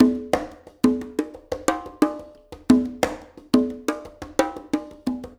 SMP CNGAS1-L.wav